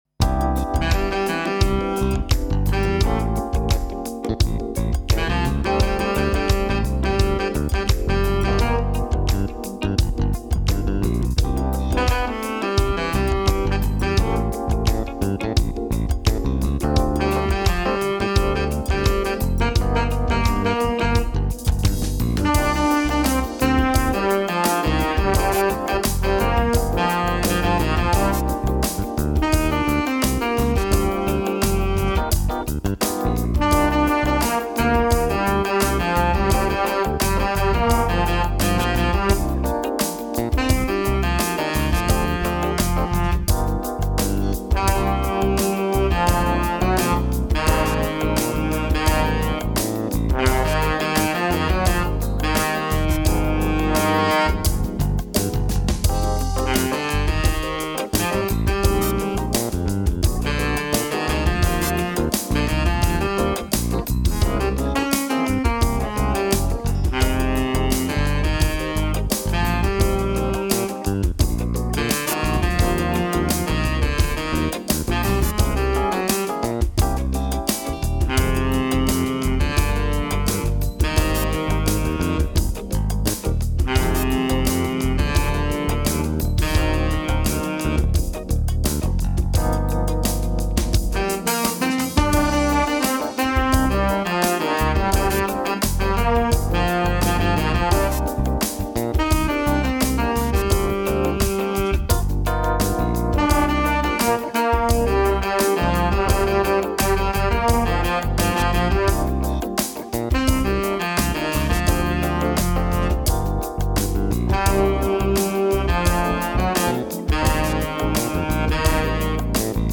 Instrumental Disco